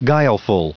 Prononciation du mot guileful en anglais (fichier audio)
Prononciation du mot : guileful